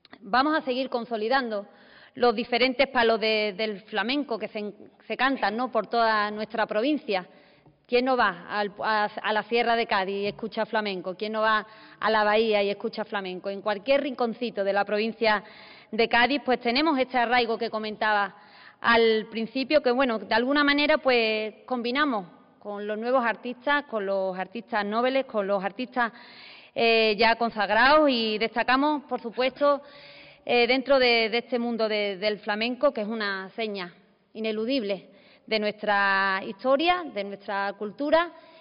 Los detalles del calendario de recitales y actividades complementarias han sido ofrecidos en una rueda de prensa en la Diputación de Cádiz en la que ha intervenido la diputada de Cultura, Vanesa Beltrán; acompañada por la delegada territorial de Turismo, Cultura y Deporte de la J
vanesa-beltra-bienal.mp3